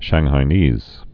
(shănghī-nēz, -nēs, shäng-)